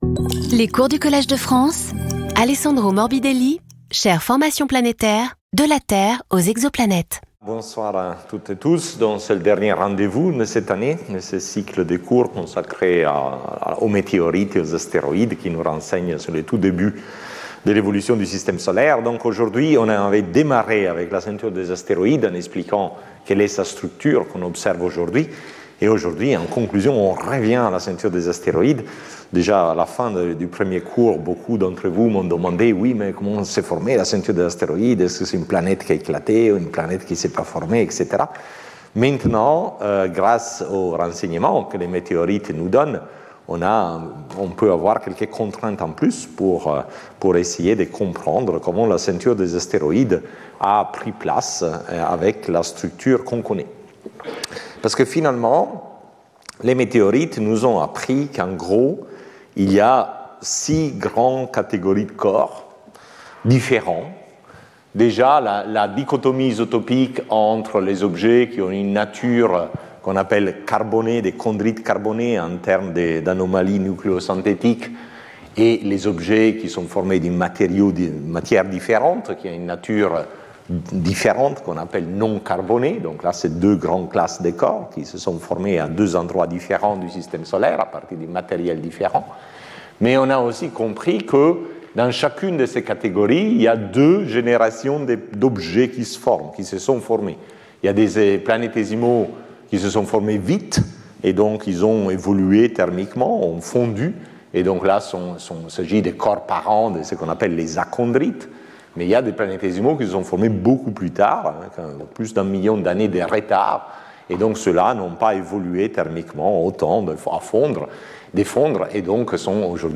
Alessandro Morbidelli Professor at the Collège de France
Lecture